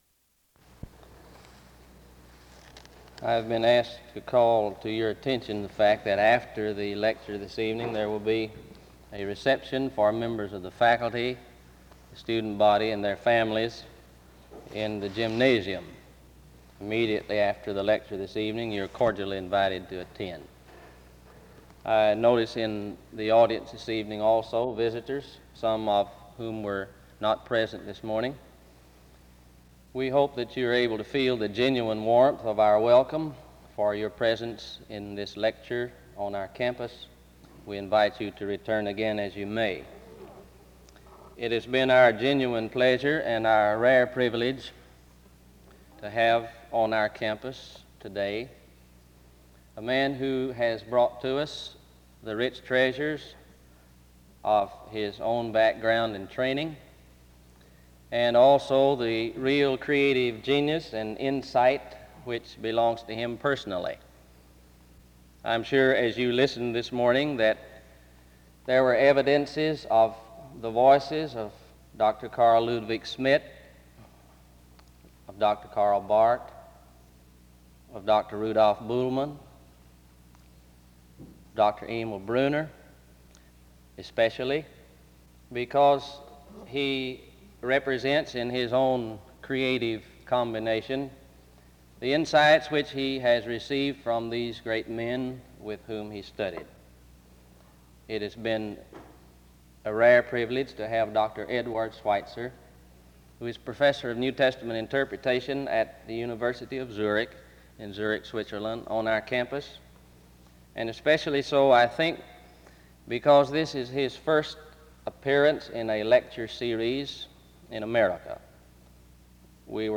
SEBTS Convocation - Eduard Schweizer September 10, 1959 PM